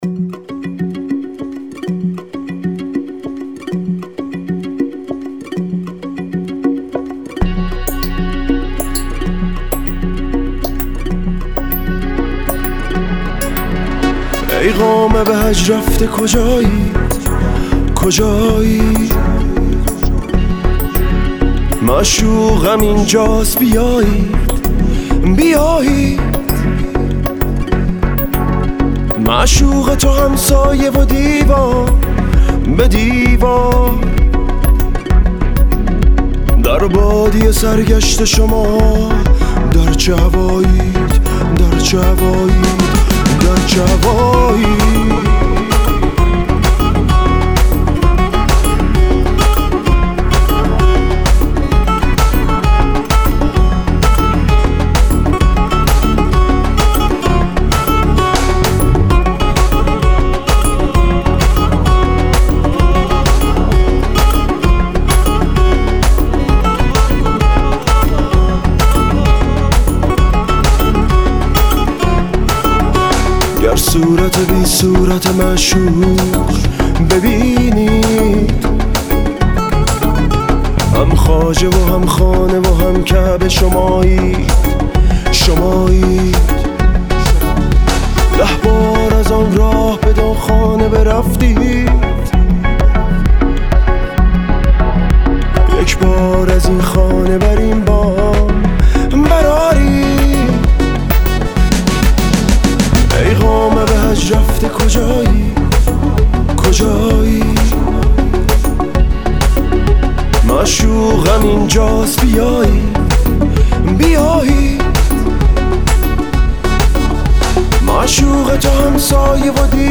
پیانو ……………………………………………………………………………………….
سه تار …………………………………………………………………………………..
کمانچه …………………………………………………………………………………..